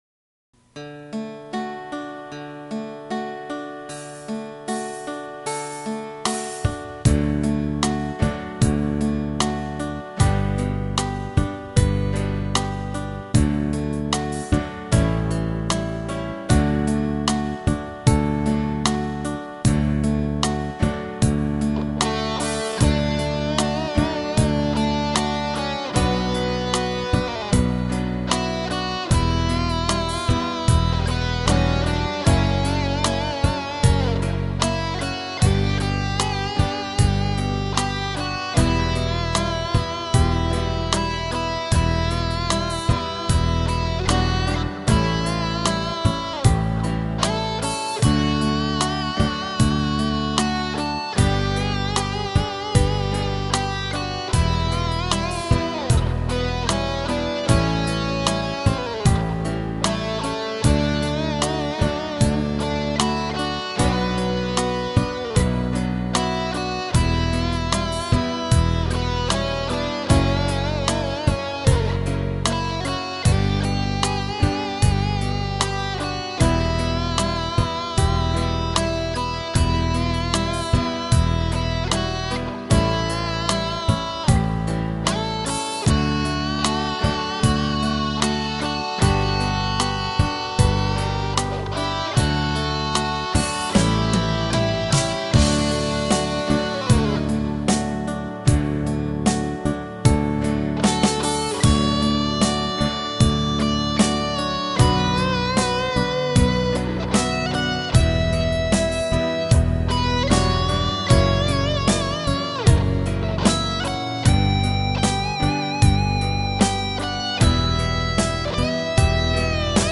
フォークソング、童謡・唱歌。